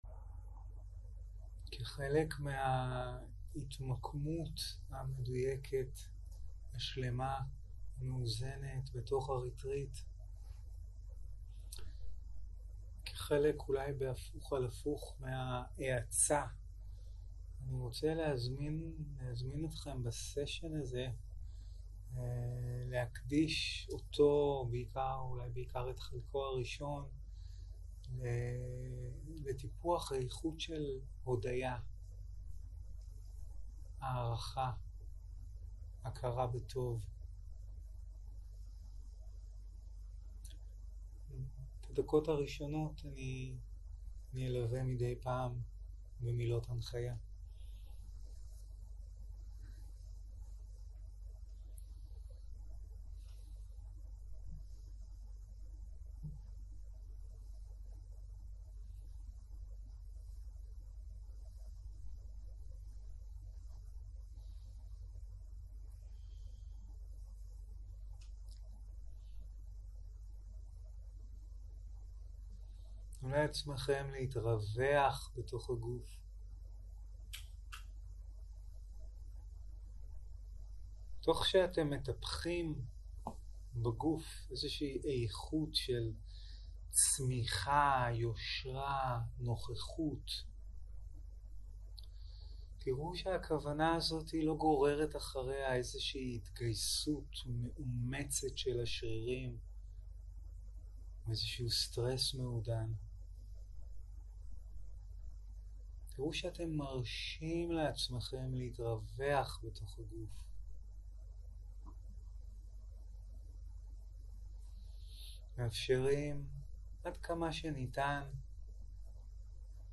יום 1 - ערב - מדיטציה מונחית - להודות על הטוב - הקלטה 1
Dharma type: Guided meditation שפת ההקלטה